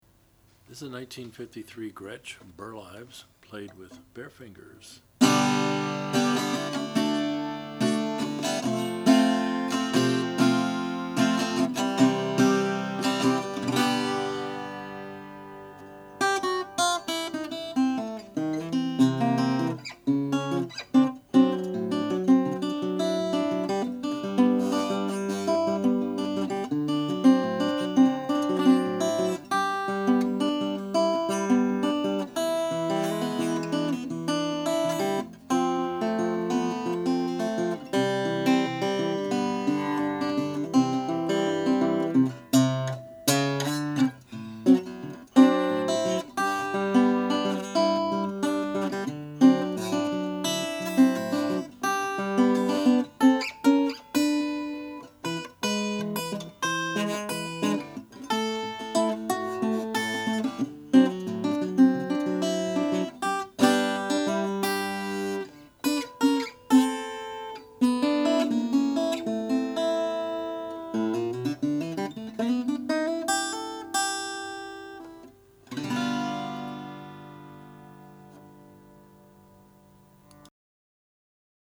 The top is solid spruce, ladder-braced with b/w/b binding and sound hole rings and features a faux-tortoise pickguard. The back and sides are laminated mahogany, with the back showing attractive grain.
Overall, this a pretty clean 00-size guitar with ladder-brace punch that retains some historic significance.